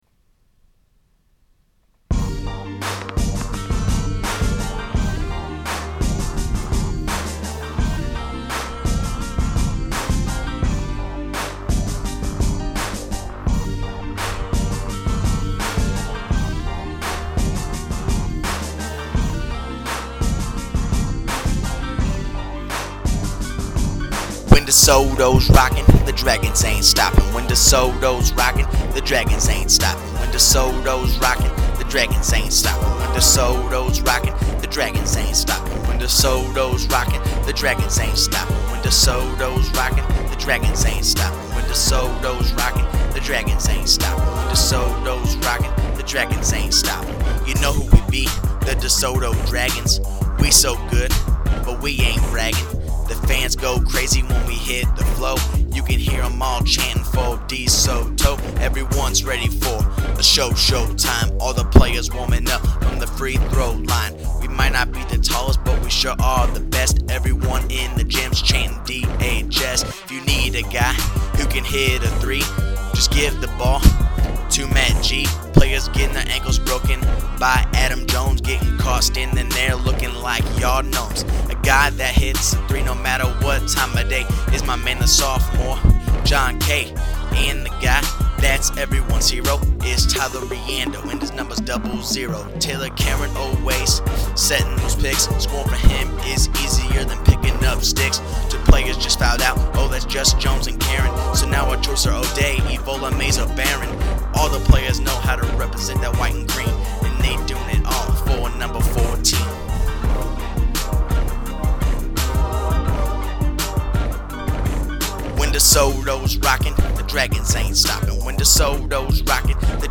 DeSoto Basketball Rap 07-08
Desoto_Basketball_Rap_07_08.mp3